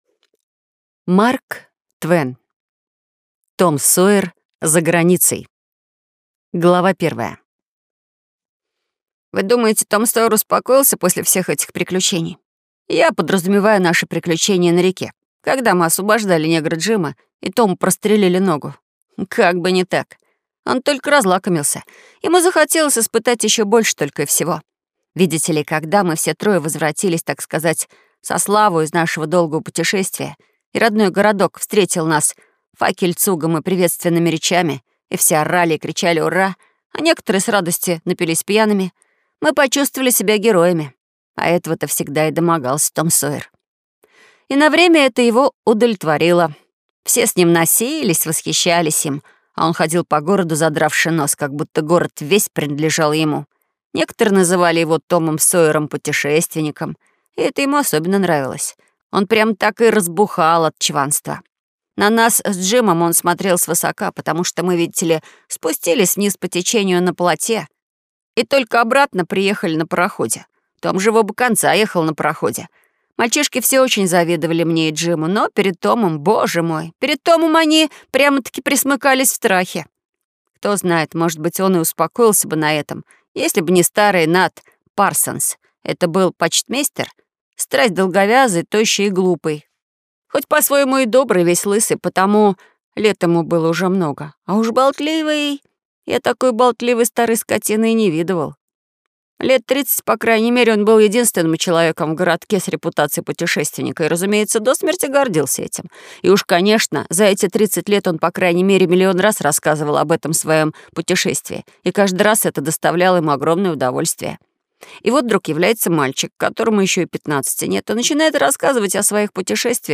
Аудиокнига Том Сойер за границей | Библиотека аудиокниг